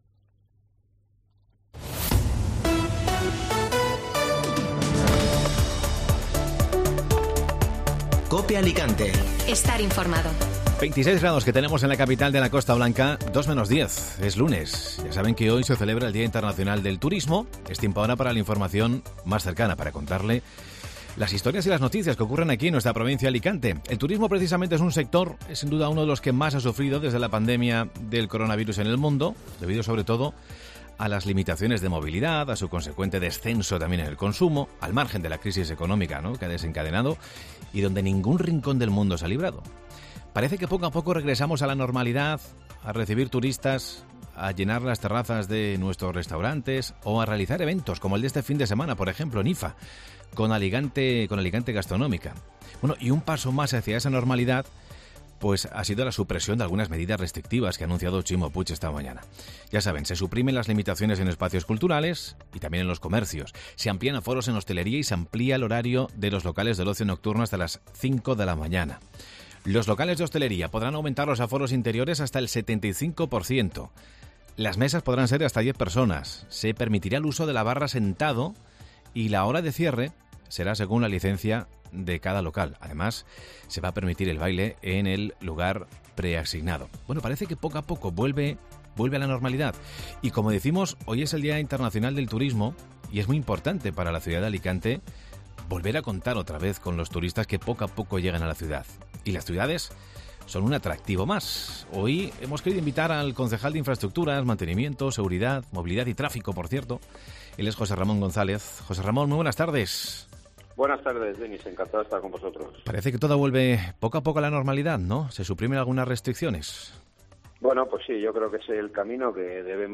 Entrevista a Jose Ramón González, edil de Infraestructuras, seguridad y tráfico